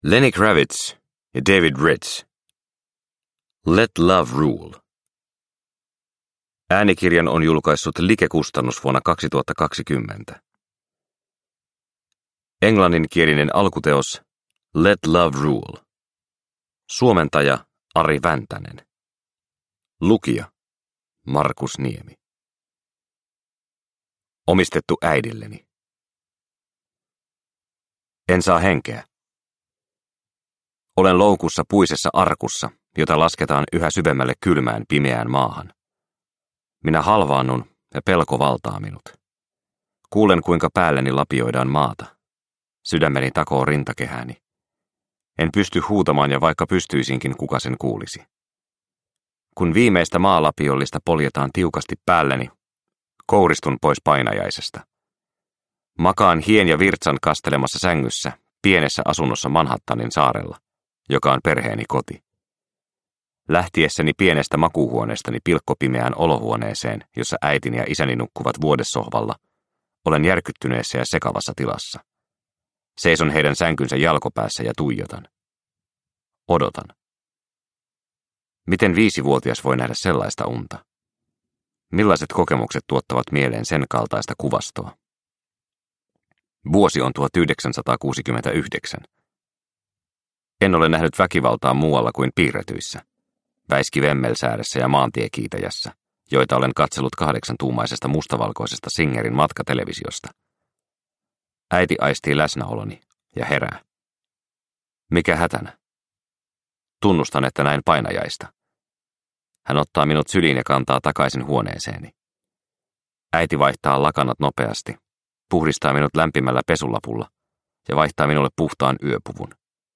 Let Love Rule – Ljudbok – Laddas ner